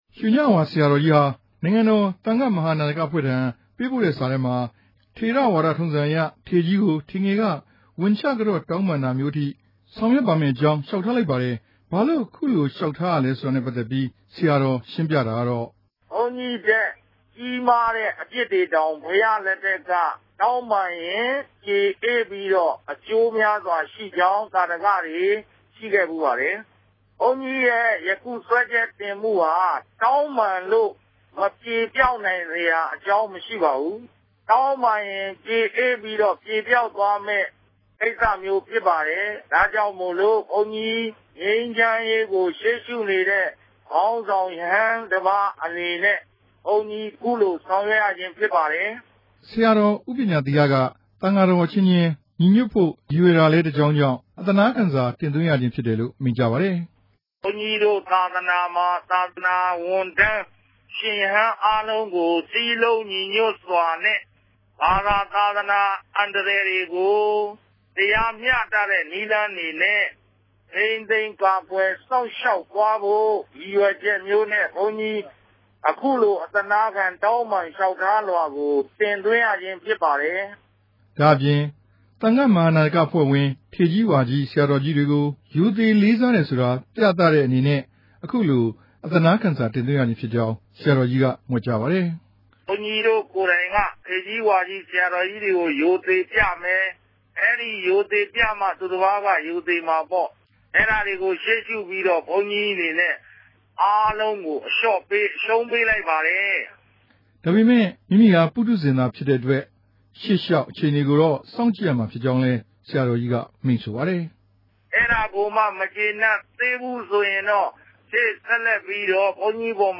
လျှောက်ထားမေးမြန်းတင်ပြချက်။